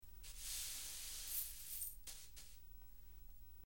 Cleaning, Sweeping
Light Sweeping Up Of Glass Pieces On Floor, X7